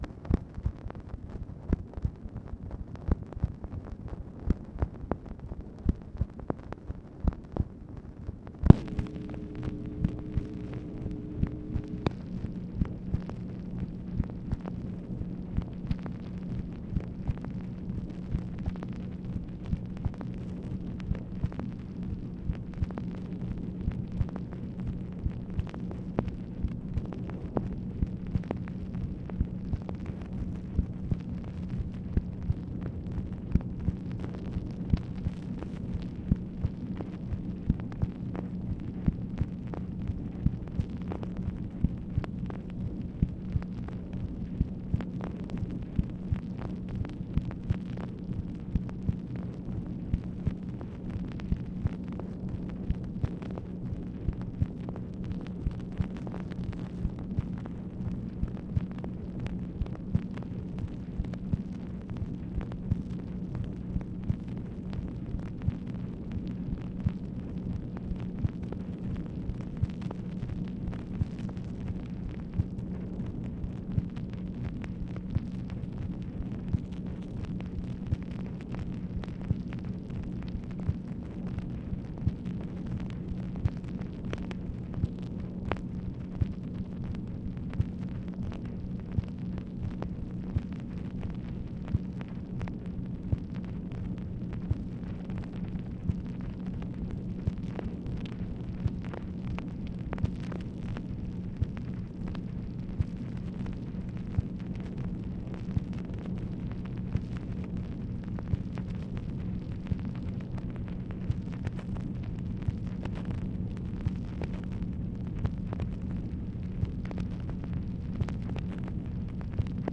MACHINE NOISE, November 16, 1964